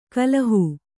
♪ kallahū